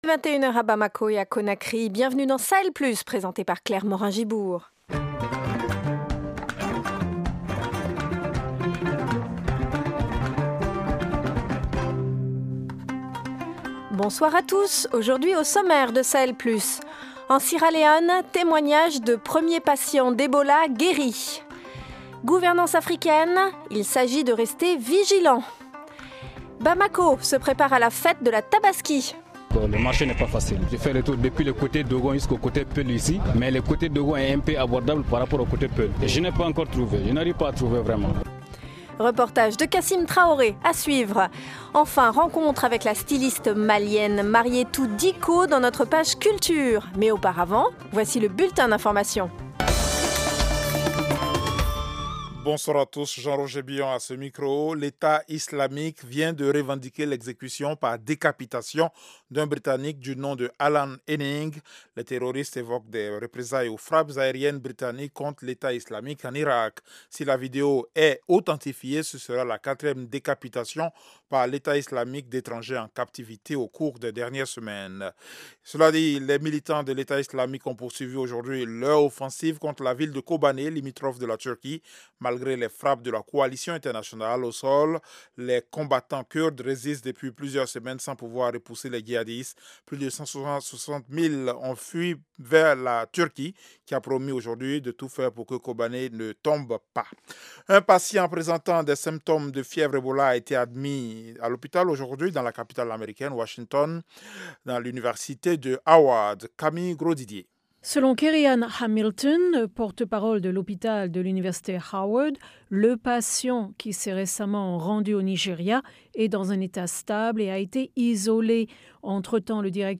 Au programme : en Sierra Leone, témoignages de premiers patients d’Ebola guéris. Gouvernance africaine : il faut rester vigilant ! Bamako se prépare à la fête de la Tabaski.